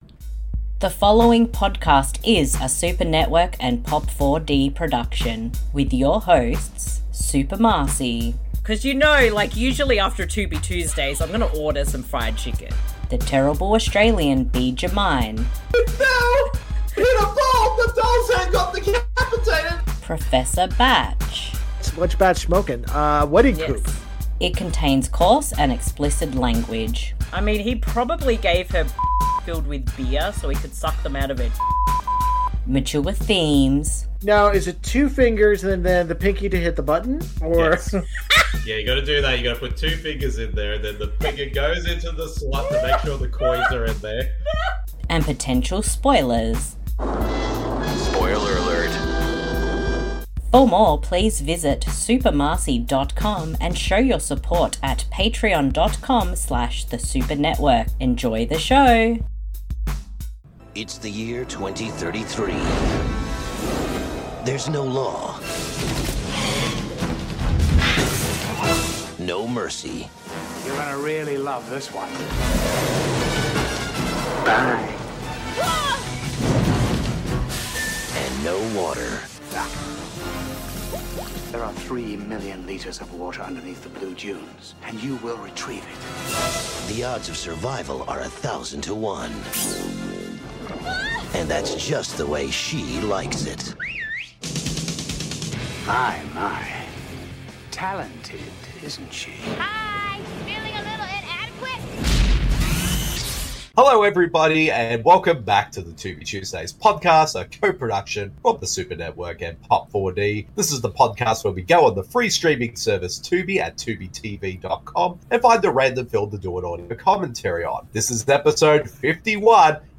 Hello everyone and welcome back to The Tubi Tuesdays Podcast, your number 1 Tubi related podcast that is hosted by two Australians and one Canadian!